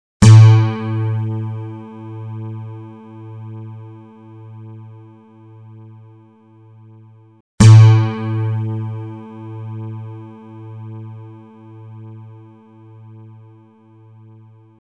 Pst... Klicka på tonerna så kan du stämma din gitarr efter ljudet!